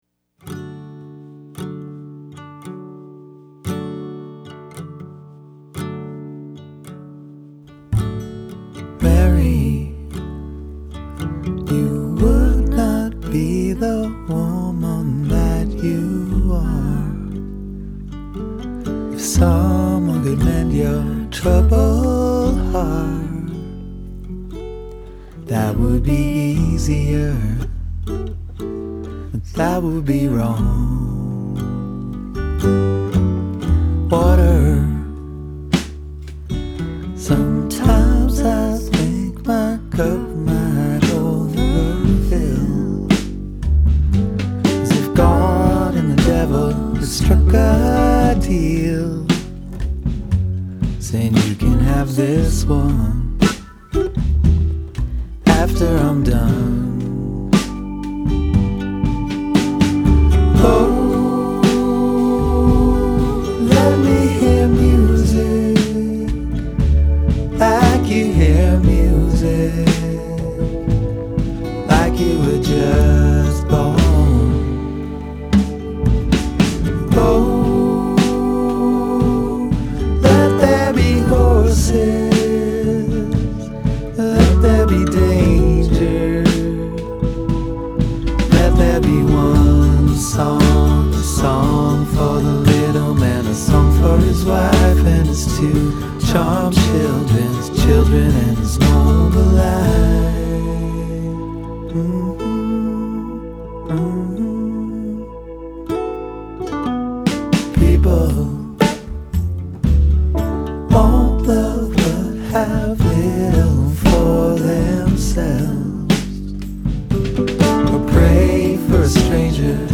Though they fit into the all encompassing genre of Americana